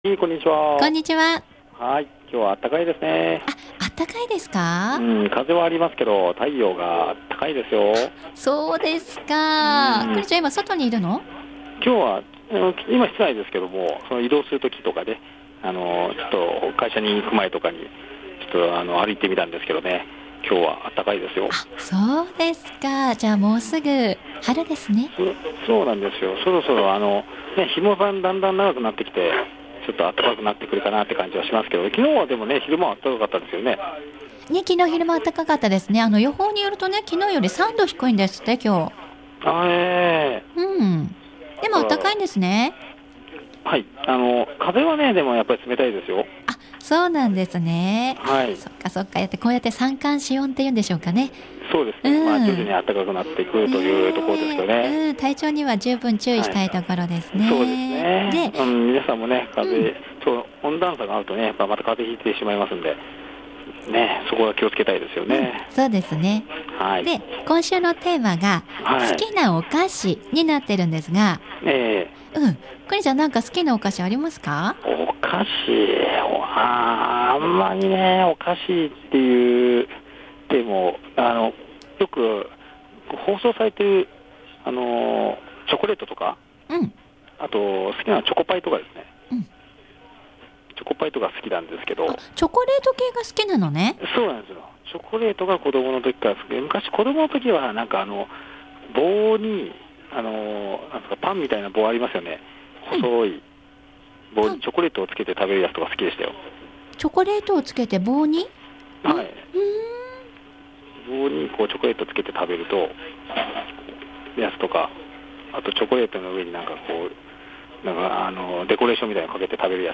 お二人とも話上手で、中継が終わった後でもゆっくりしていたいと思いました。